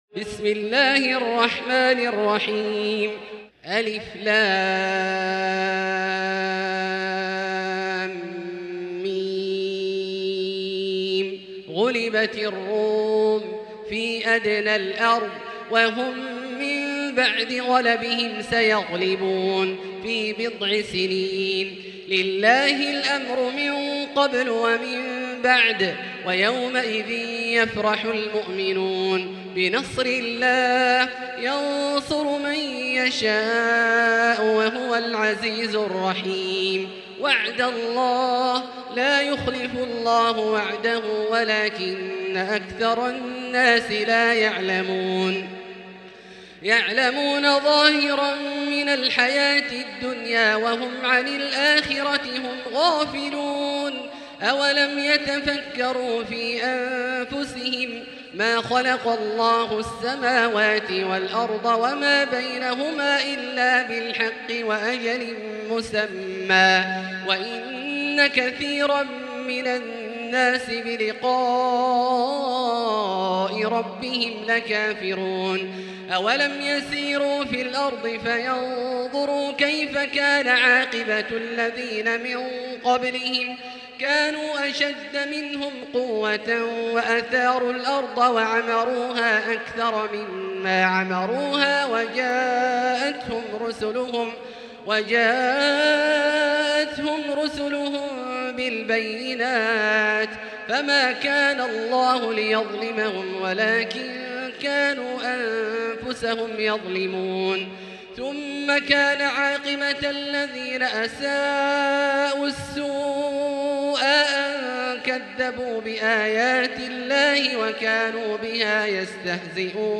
المكان: المسجد الحرام الشيخ: فضيلة الشيخ عبدالله الجهني فضيلة الشيخ عبدالله الجهني فضيلة الشيخ ياسر الدوسري الروم